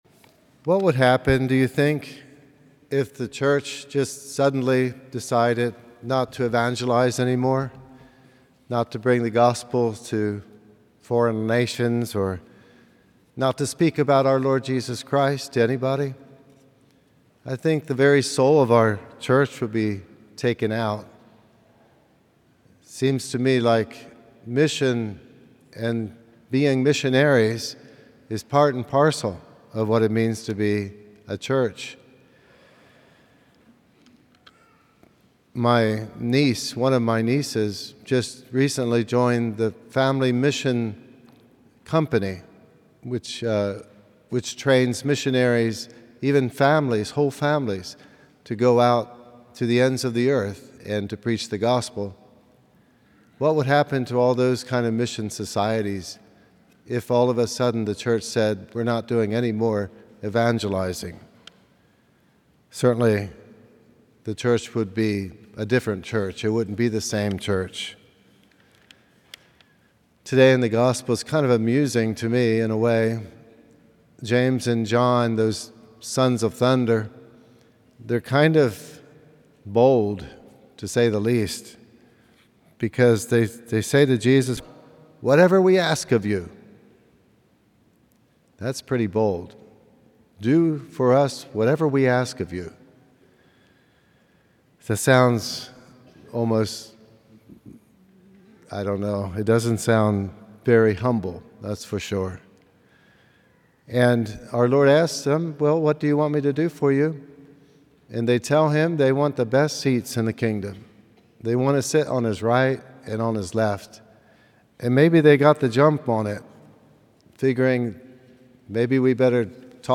From Series: "Homilies"